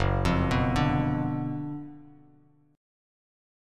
GbM7sus4#5 Chord